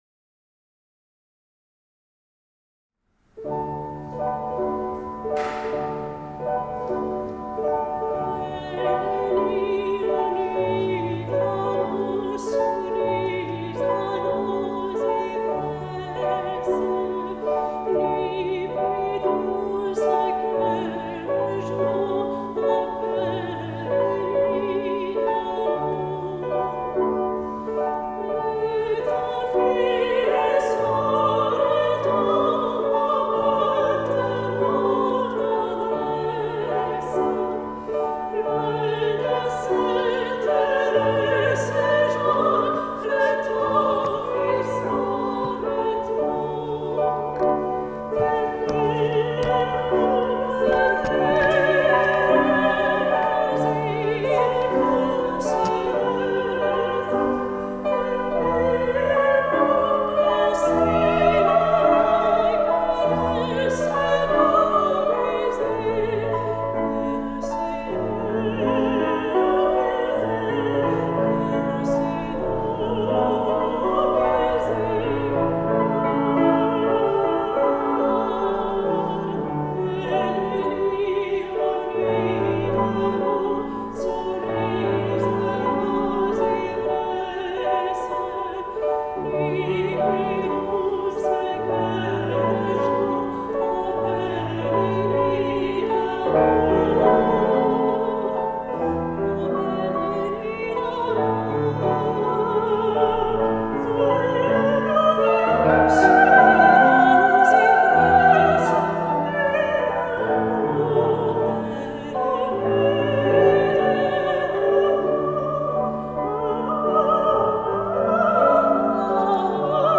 Alto
Mezzo-soprano
Piano
Le trio d'Elles, ce sont trois femmes, deux voix et un piano qui vous proposent de beaux et émouvants moments de musique.